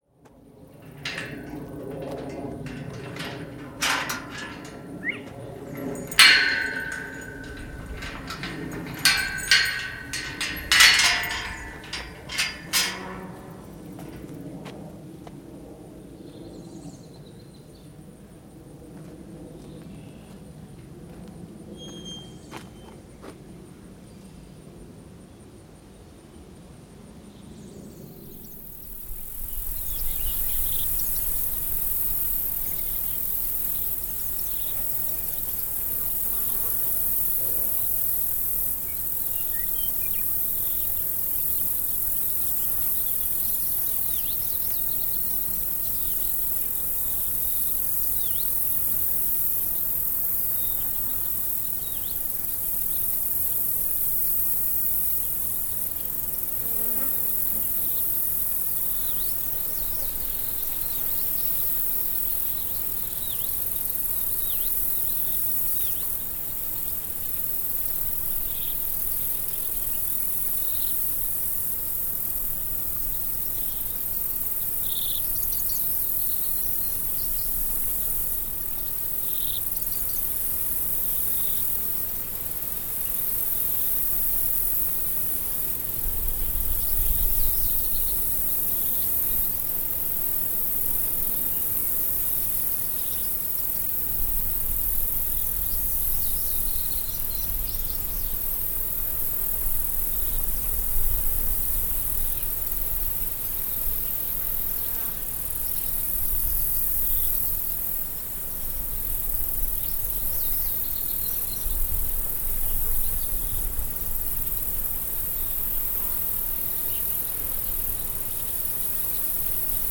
Three field recordings (gate, birds, beetles) from a project at the Salina Art Center, Salina, KS (2012)
Three Field Recordings (Kansas, gate, birds, beetles).mp3  from a project at the Salina Art Center, Salina, KS (2012)